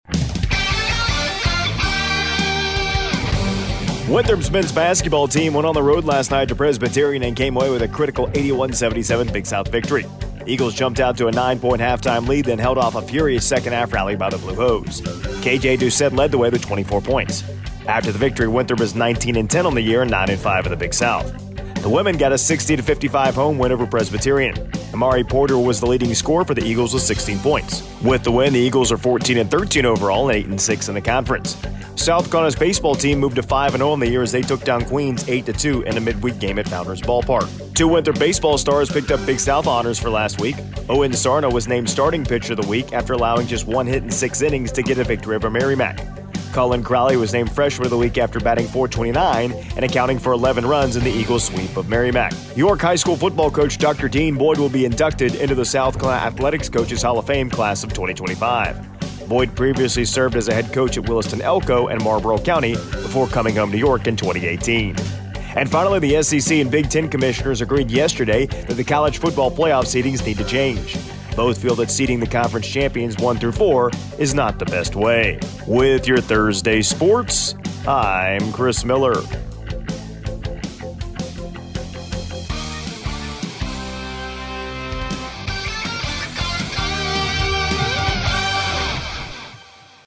AUDIO: Monday Morning Sports Report